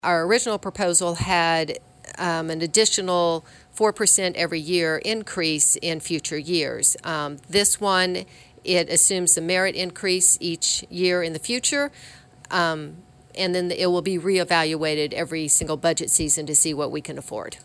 City Treasurer Janet Harouff says she believes this is a fair compromise as it allows the city to better compensate their employees while not locking future commissions into any long-term agreements they may not be able to afford in future years.